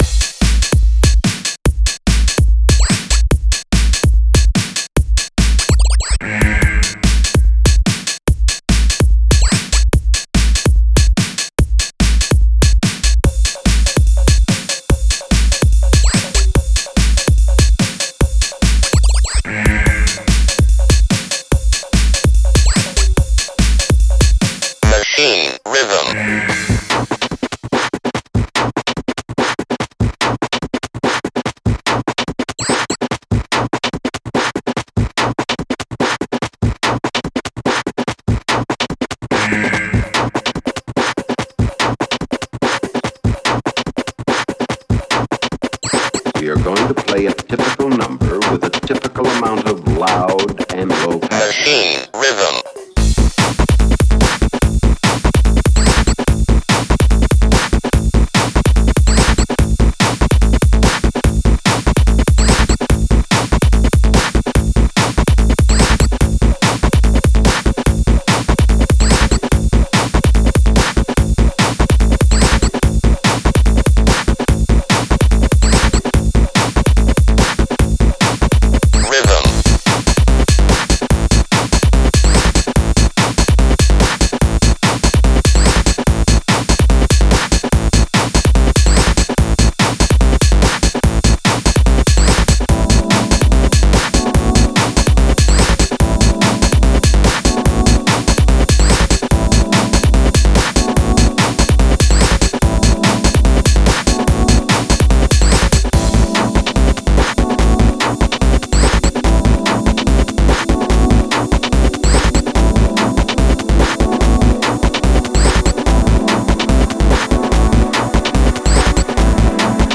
A shorter breakbeat song.